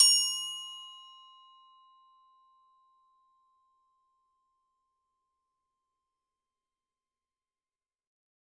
Hard_plastic_f_C4.wav